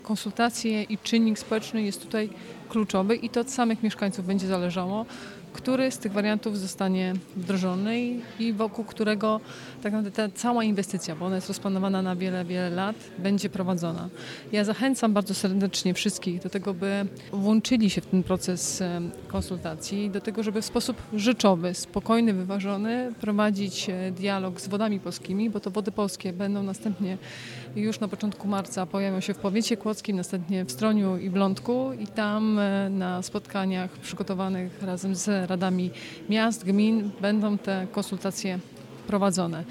– Konsultacje społeczne to czynnik kluczowy – podkreśla wojewoda dolnośląska Anna Żabska.